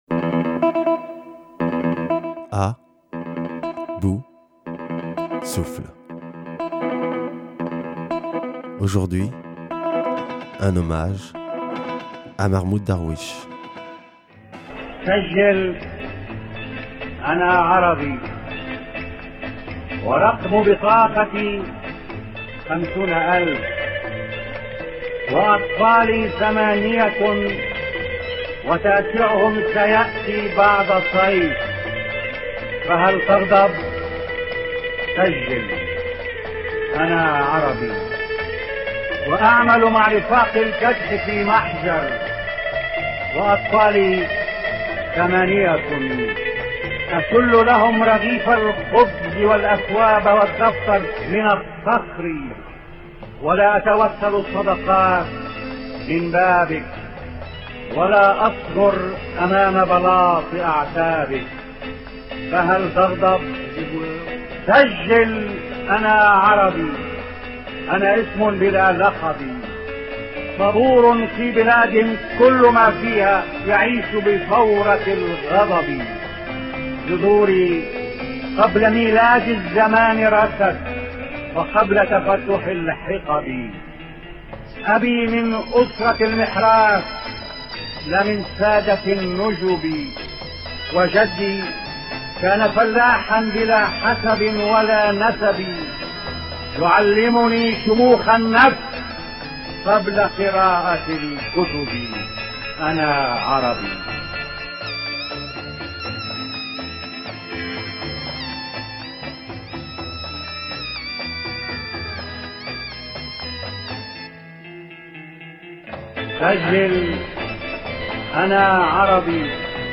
R�cital